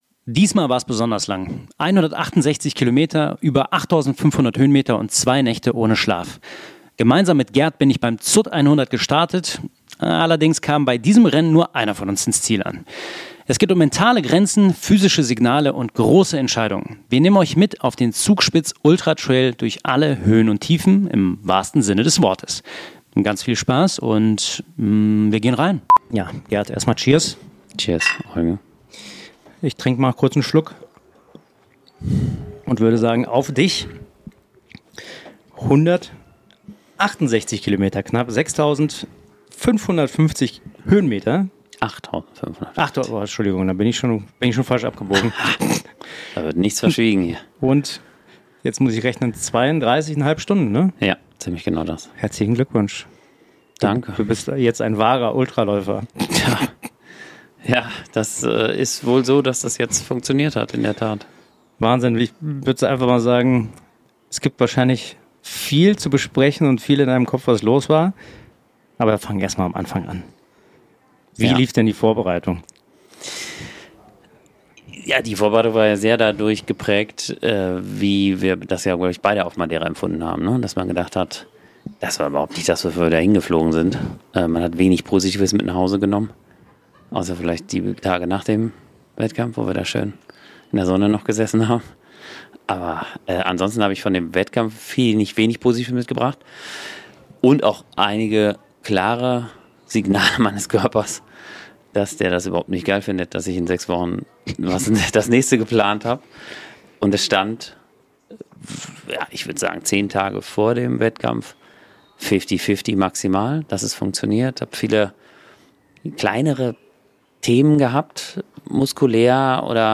In dieser Folge sprechen die beiden über Zweifel und Zuversicht, mentale Strategien, den Umgang mit Hitze, das richtige Timing beim Ausstieg – und das Gefühl, allein durch zwei Nächte in den Alpen zu laufen. Eine Folge über Selbstfürsorge, Grenzerfahrung und ein Finish, das so schnell nicht vergessen wird.